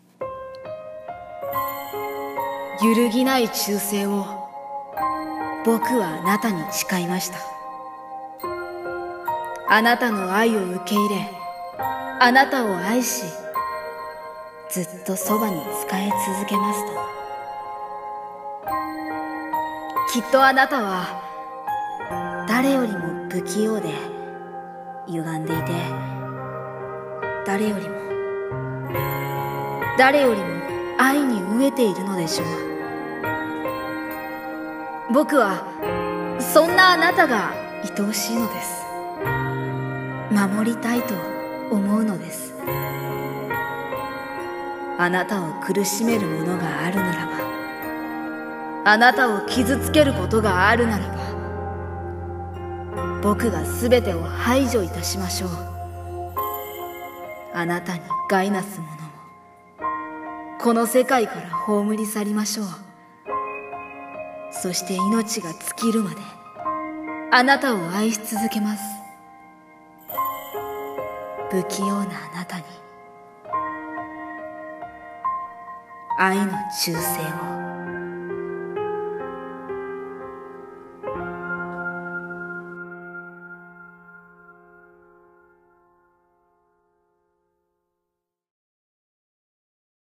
【声劇朗読】